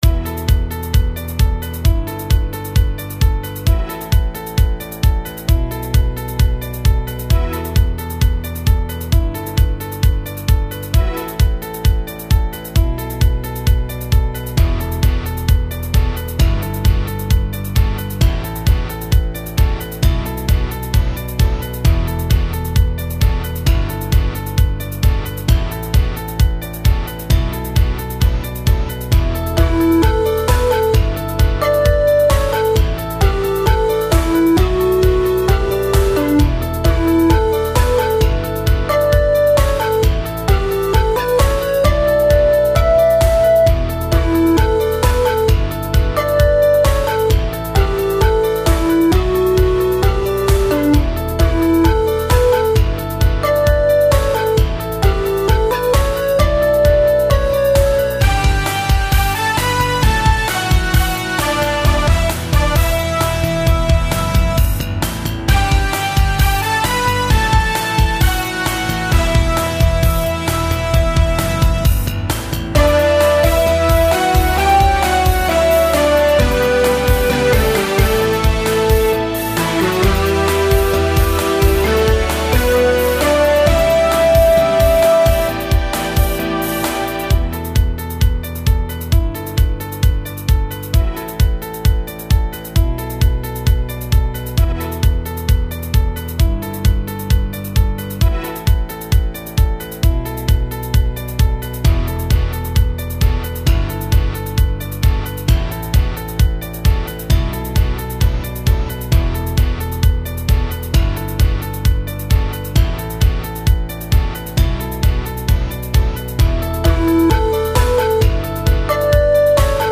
今回の曲は、ピアノのアルペジオきっかけで作り始めた感じか。黒鍵だけでアルペジオのループ作ったんで、ペンタトニック（五音階）のフレーズになってて、何となく民俗音楽的になるというか、ちょっと暗いというか妖しいというか、独特の感じになってる。
それにベースがクリシェ気味に音を上げていってるから、冗長な感じにはなってないのだろうな。
ピアノやギター、ベースとドラムもだけど、各セクションに応じてリズムや演奏方法を変えてやってみたが、どうやらこういう感じでいいのかもしれない。